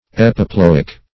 Epiploic \Ep`i*plo"ic\